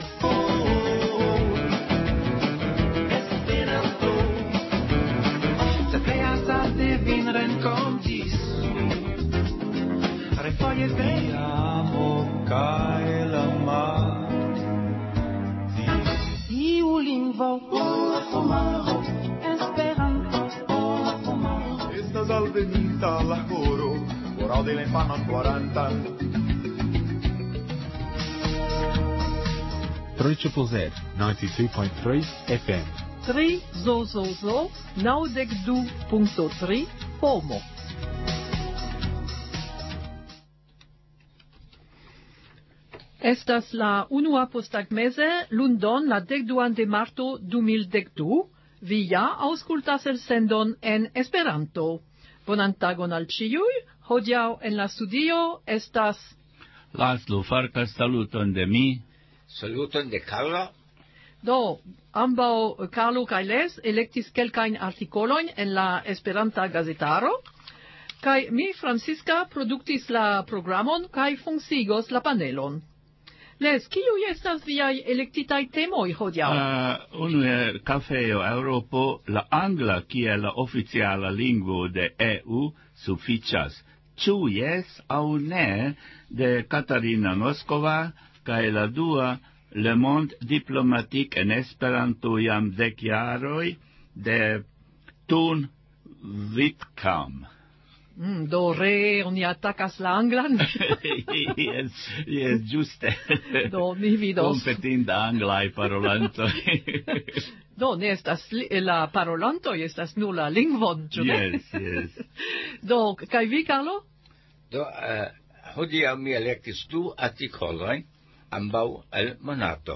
Intervjuo